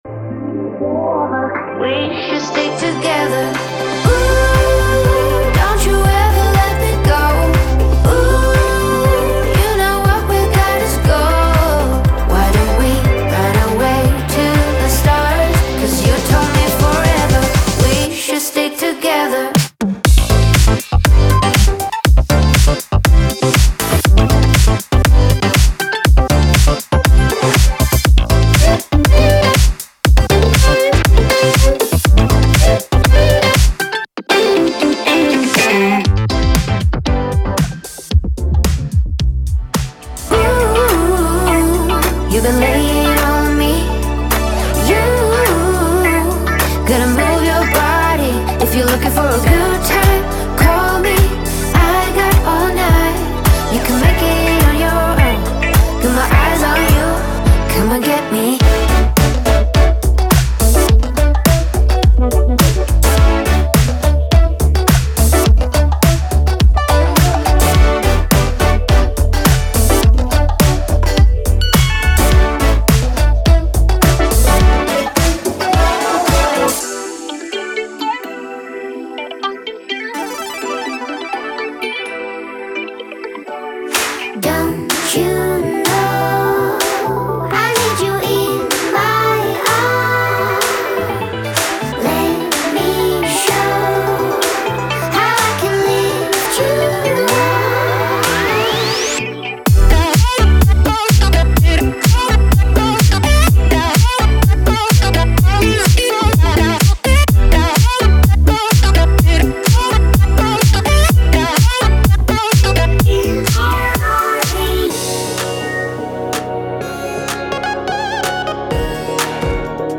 Genre:Nu Disco
本作は、グルーヴ感あふれるモダンディスコのマスタークラスとも言える内容です。
リッチなコードからパンチの効いたリードやベースまで幅広くカバーされています。
デモサウンドはコチラ↓
5 x Vocal Hooks